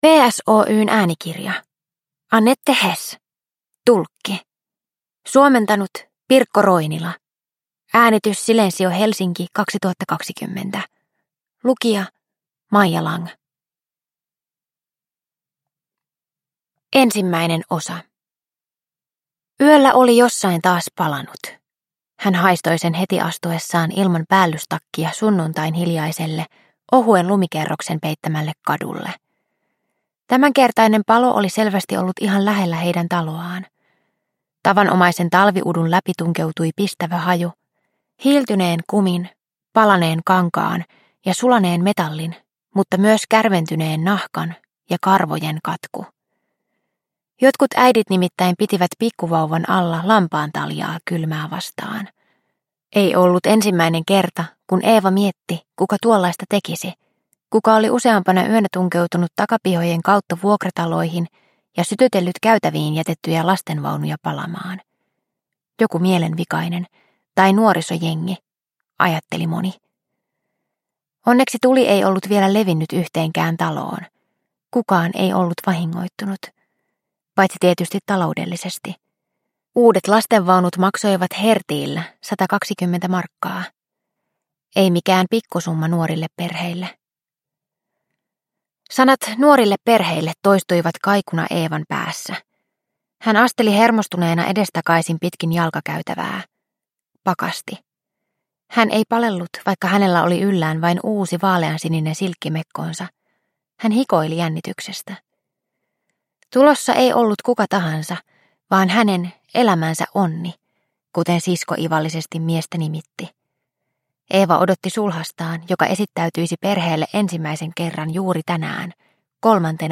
Tulkki – Romaani Auschwitzin oikeudenkäynneistä – Ljudbok – Laddas ner